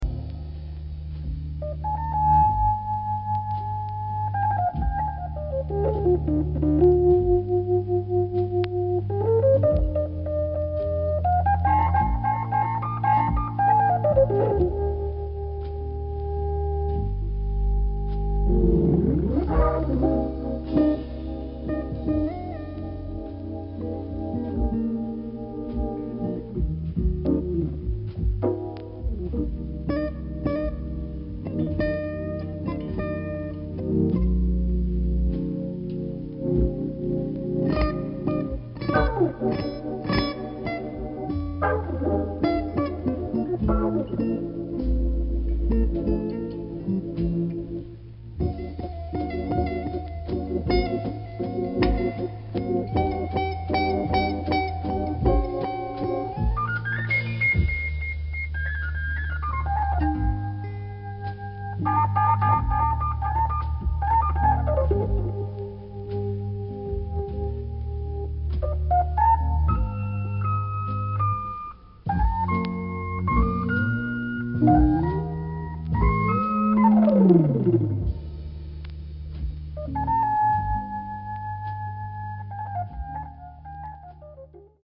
Je trouve également une certaine mélancolie dans son jeu.